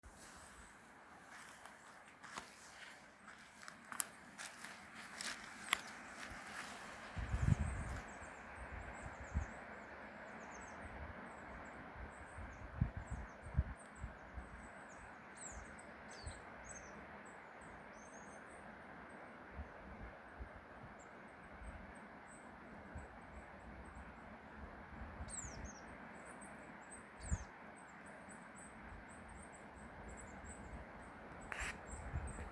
Birds -> Tits ->
Crested Tit, Lophophanes cristatus